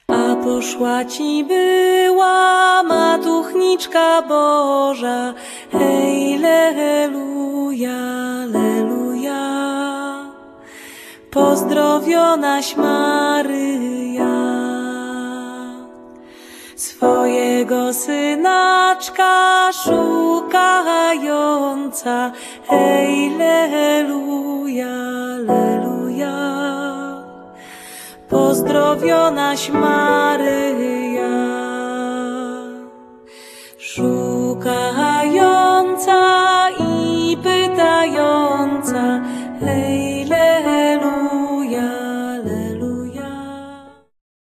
instrumenty klawiszowe, kontrabas, sample, loopy
altówka
perkusja